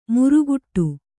♪ muruguṭṭu